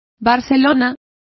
Complete with pronunciation of the translation of barcelona.